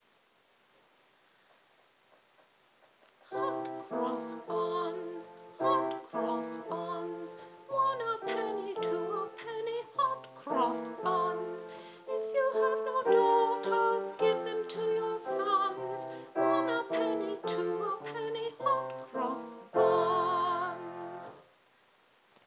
I needed a piano version.